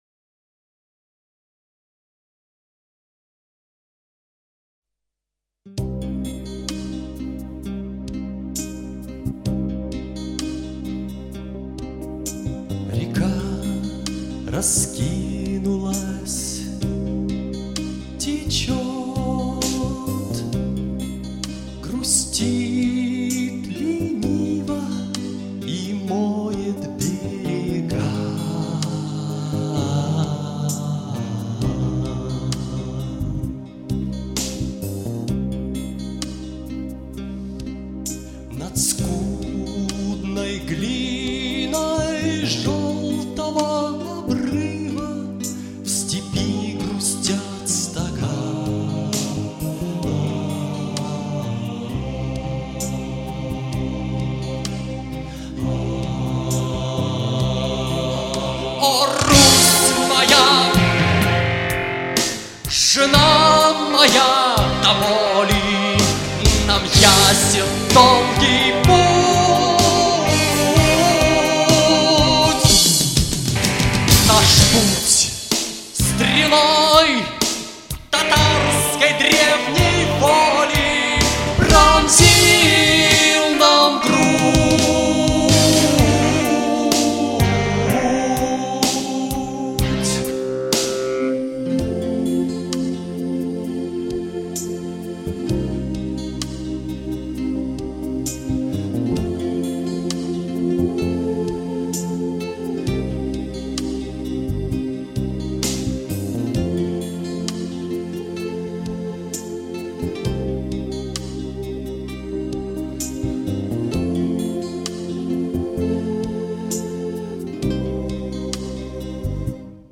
музыкальная рок-композиция
синтезаторы